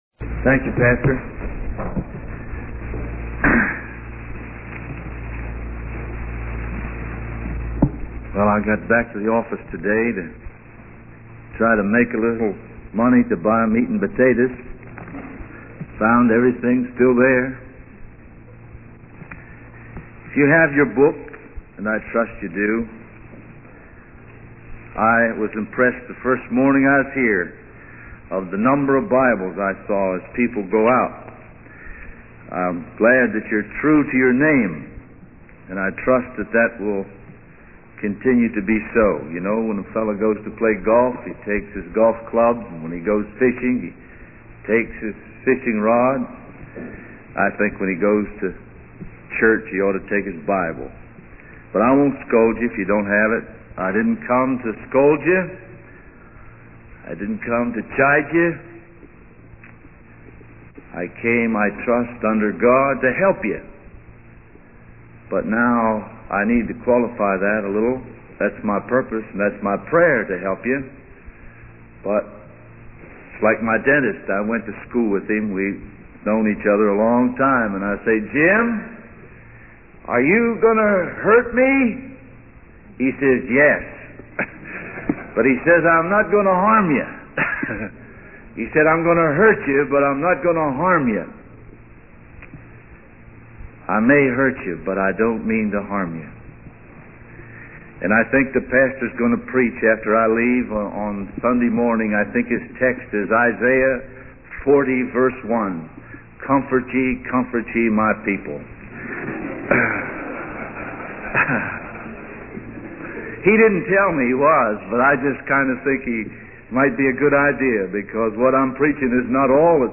In this sermon, the speaker emphasizes the importance of having the right motive, message, and approach when preaching the gospel.